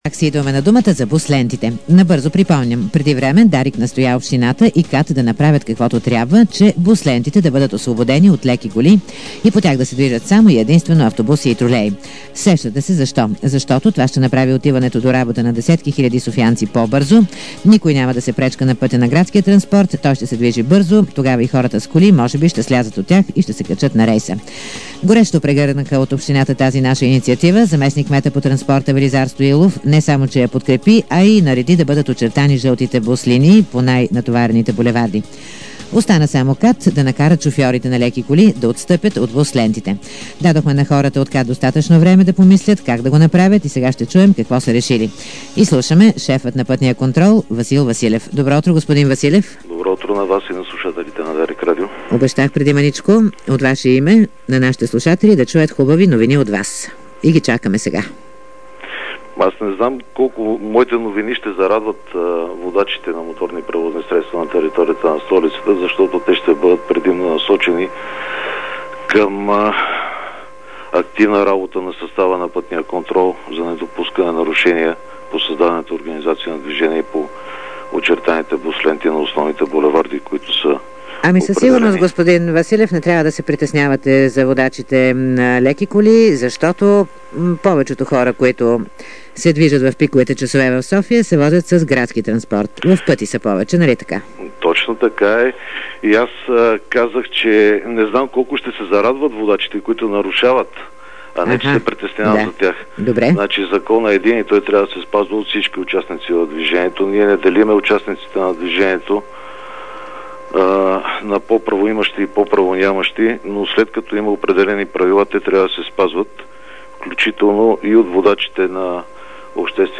DarikNews audio:Интервю